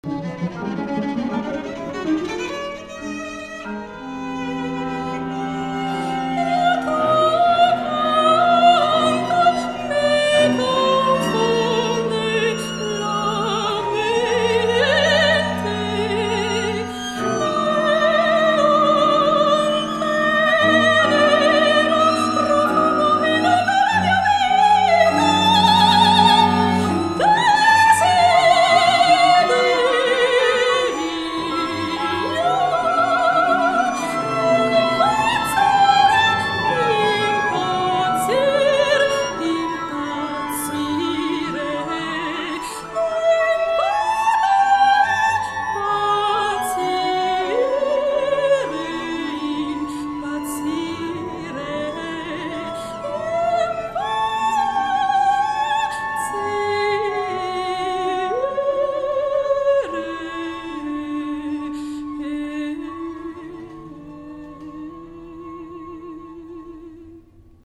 concerto dal vivo
audio 44kz stereo